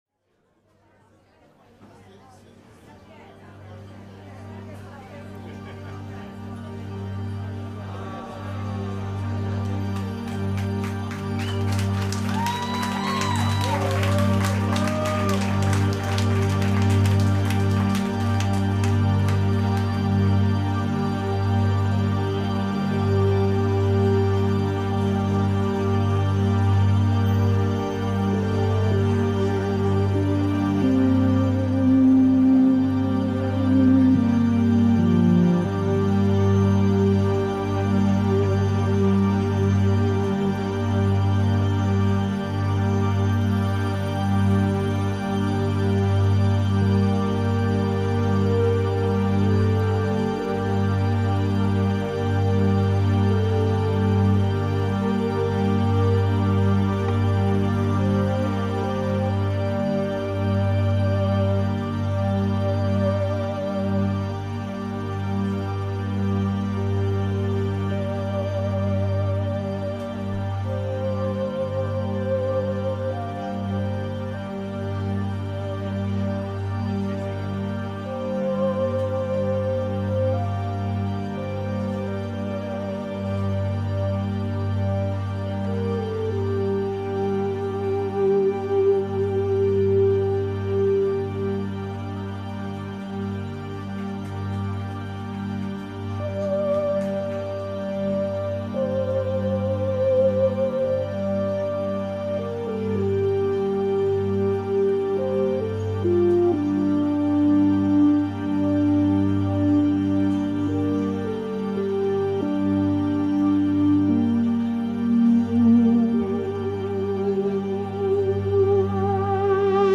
Live Cover (no post processing).
GUITARS
PIANO and KEYBOARDS
BASS